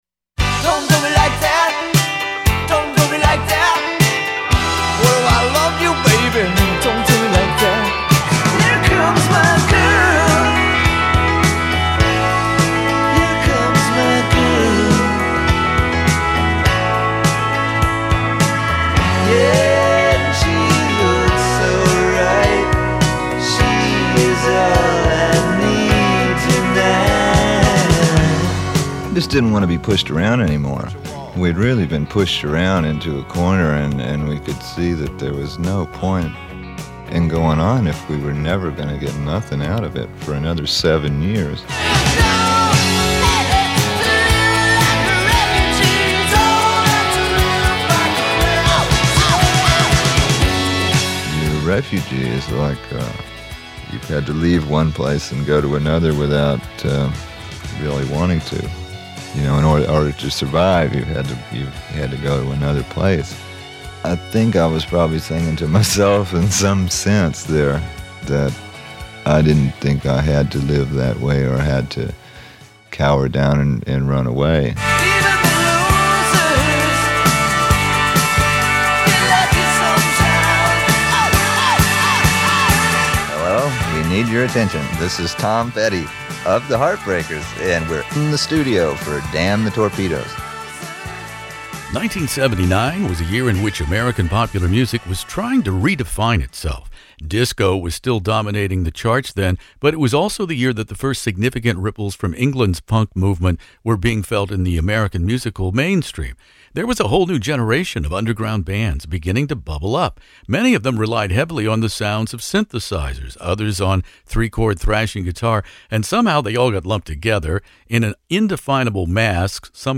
I hope I never have to go through that again!” exclaimed the late Tom Petty to me in my classic rock interview documenting Tom Petty and the Heartbreakers’ breakthrough third album in October 1979, Damn the Torpedoes.